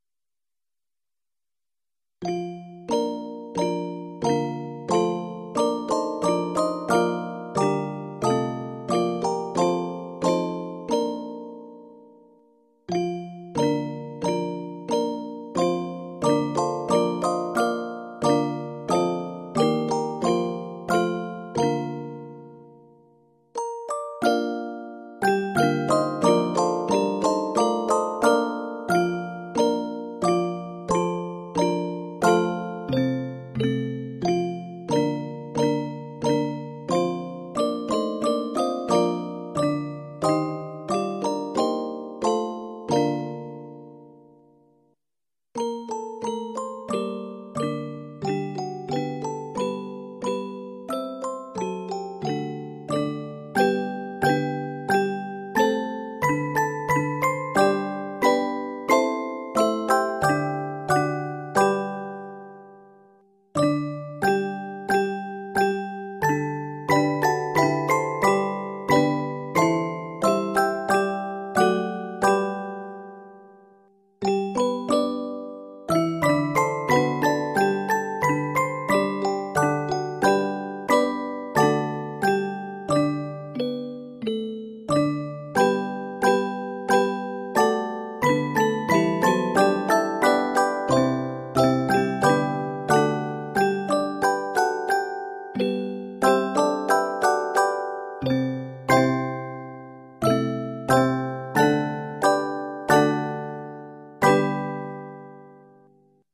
Set in Bb Major and G Major, it is 40 measures.